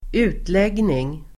Uttal: [²'u:tleg:ning]